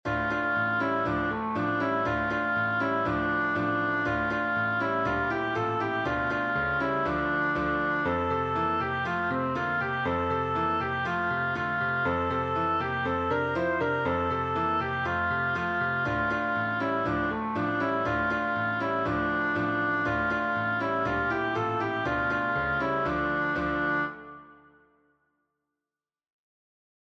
Hebrew folk song